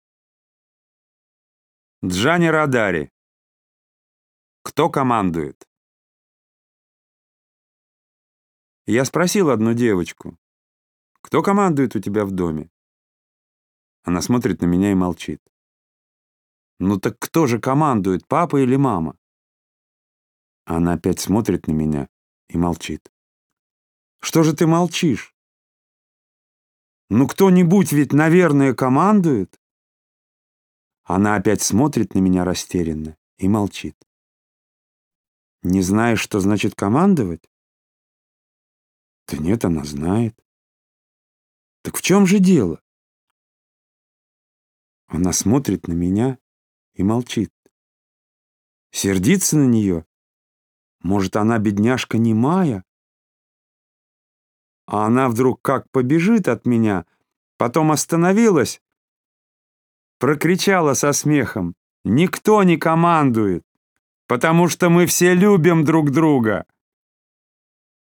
Аудиосказка «Кто командует?»